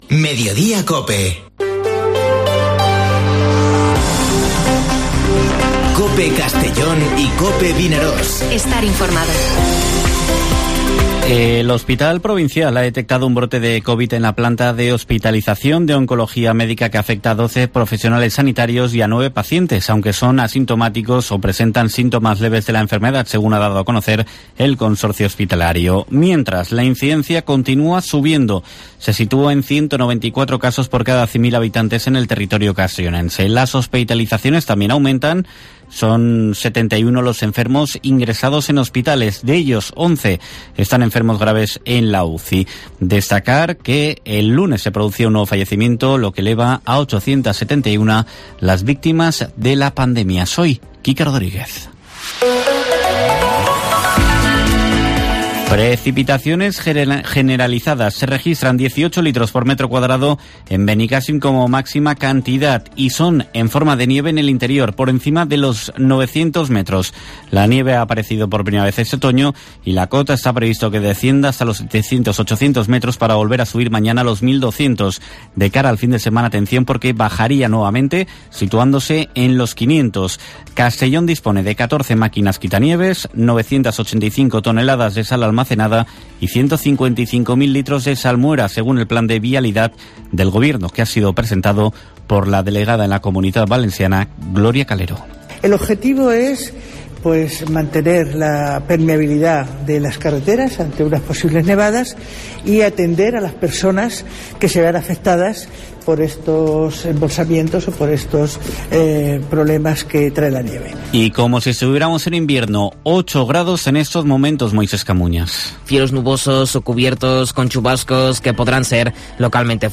Informativo Mediodía COPE en la provincia de Castellón (24/11/2021)